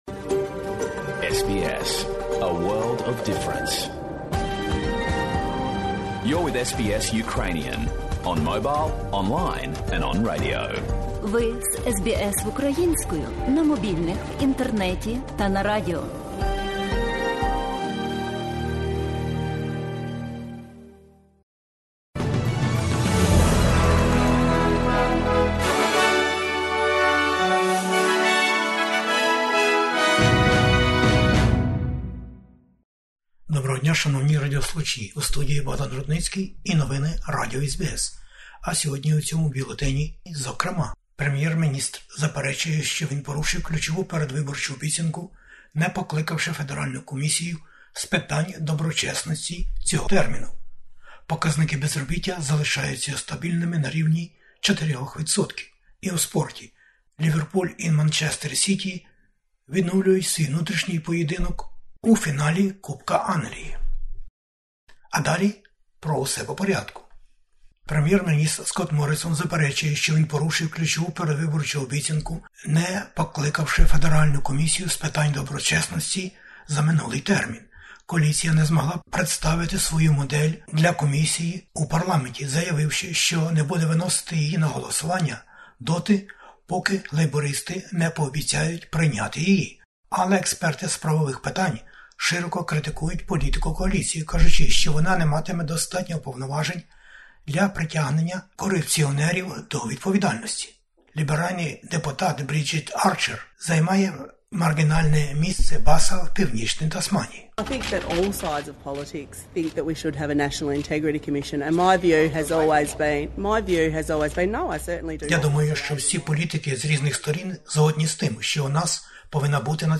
Бюлетень SBS новин українською мовою. Федеральні вибори в Австралії - 21-го травня. Передвиборчі баталії набирають обертів на 5-му континенті планети - усі лідери партій зустрічаються із виборцями та пропонують на розсуд програмні цілі державної домогосподарки на майбутнє. Війна в Україні та американські акценти про війну в Україні.